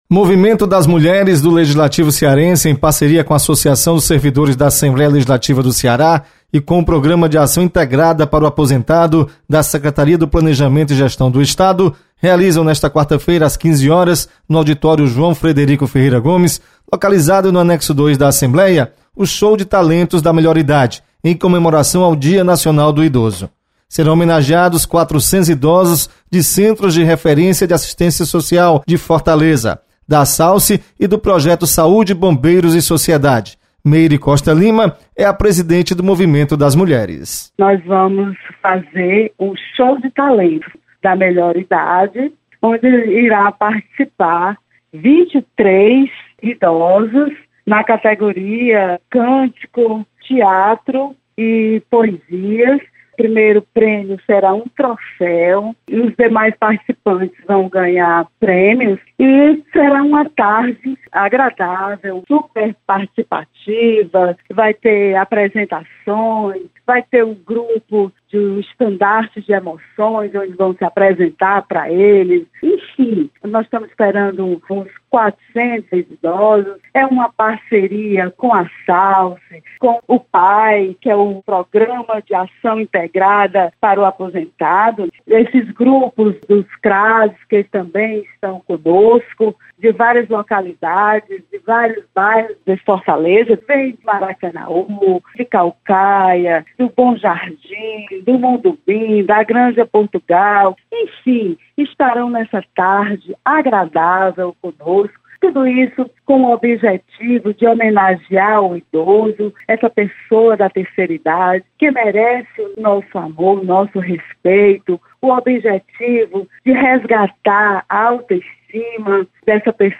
Você está aqui: Início Comunicação Rádio FM Assembleia Notícias Assalce